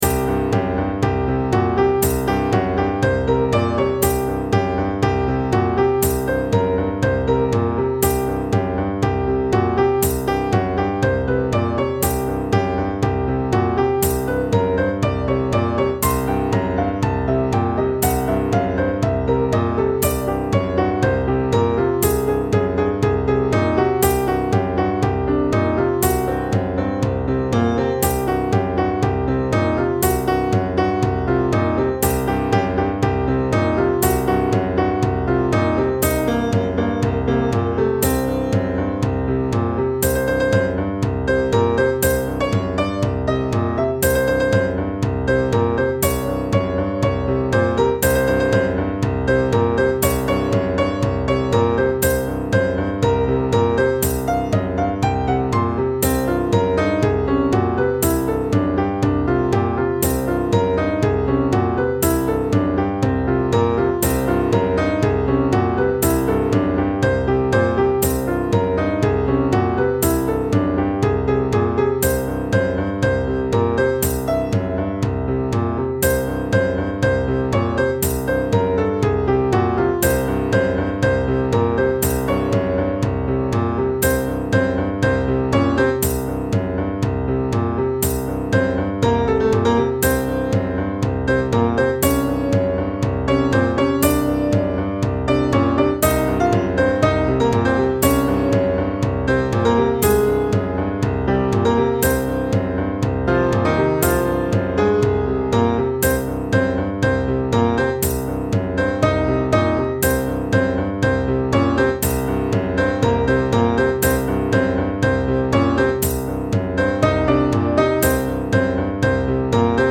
Pieza para piano
piano
Sonidos: Música